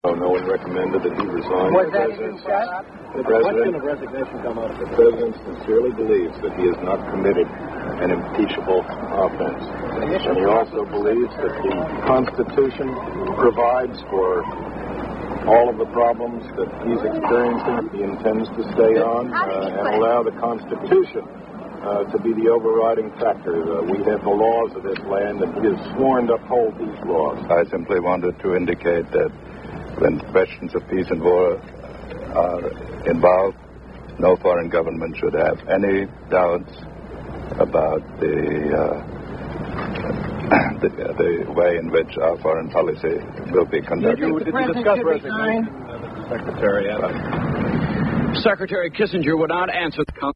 U.S. Secretary of the Treasury William Simon and Secretary of State Henry Kissinger speak in support of President Richard Nixon